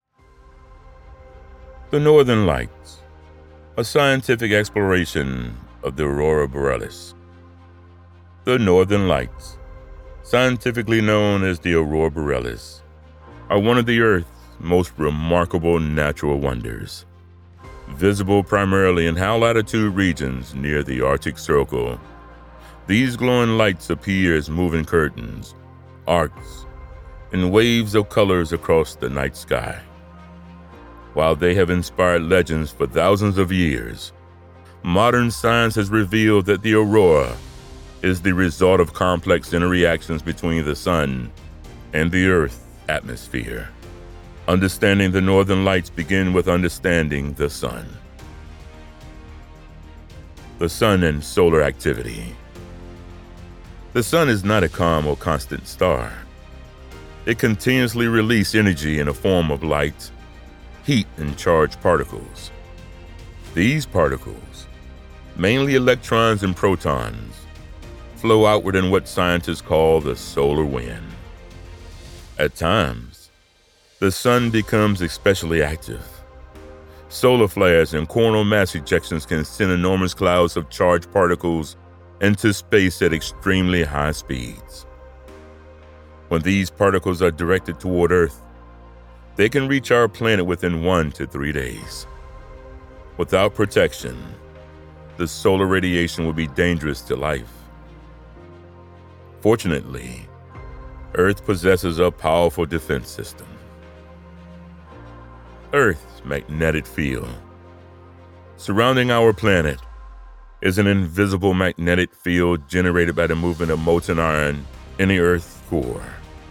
Voice for Audiobooks, Documentaries, PSA's & Stories
Adult AA Senior Male Baritone Voiceover for All Your Voice Needs